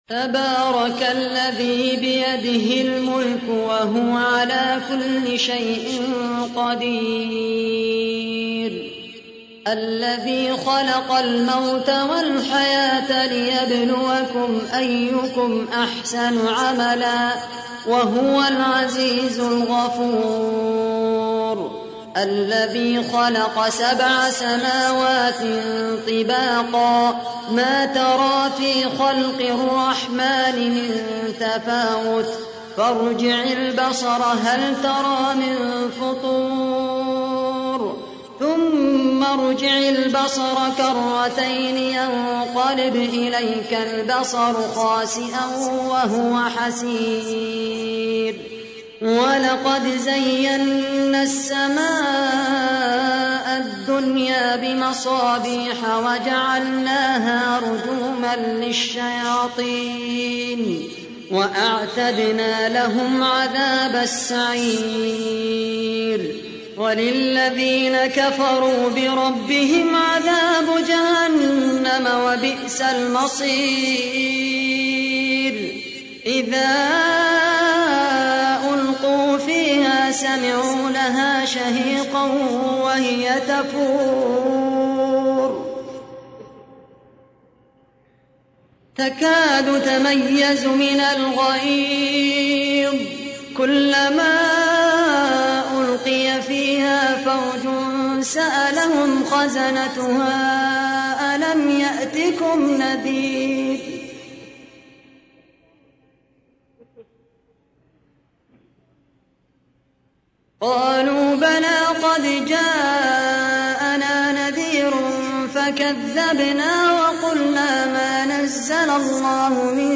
تحميل : 67. سورة الملك / القارئ خالد القحطاني / القرآن الكريم / موقع يا حسين